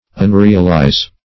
Search Result for " unrealize" : The Collaborative International Dictionary of English v.0.48: Unrealize \Un*re"al*ize\, v. t. [1st pref. un- + realize.] To make unreal; to idealize.